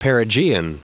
Prononciation du mot perigean en anglais (fichier audio)
Prononciation du mot : perigean